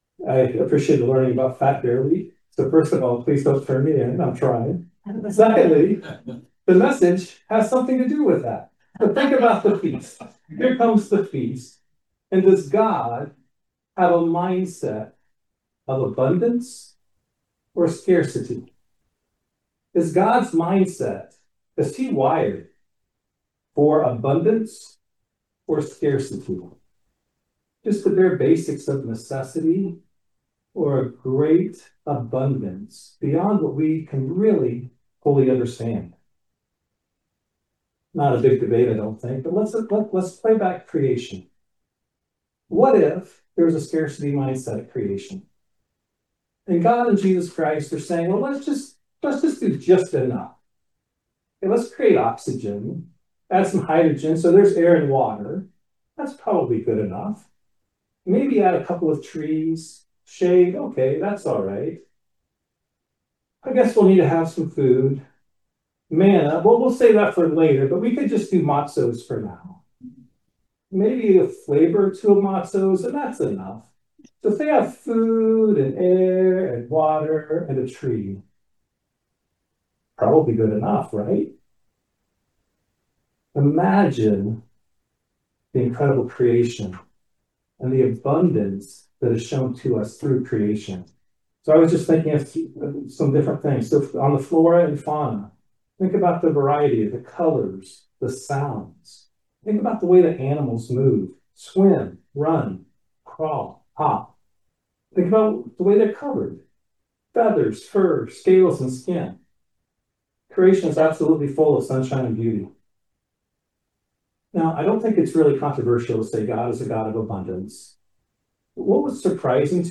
Given in Lexington, KY